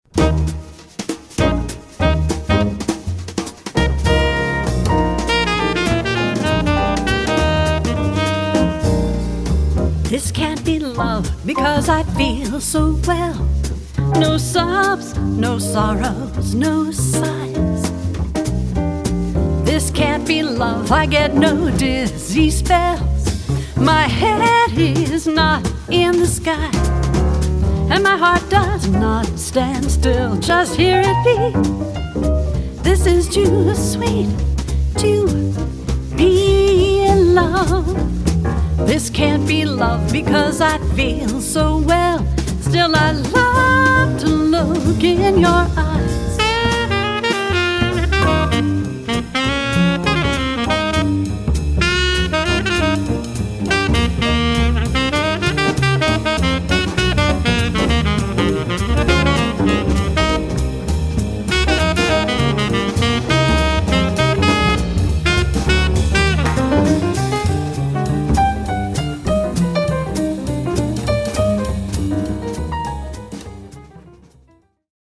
piano
vocals
bass
drums
saxophone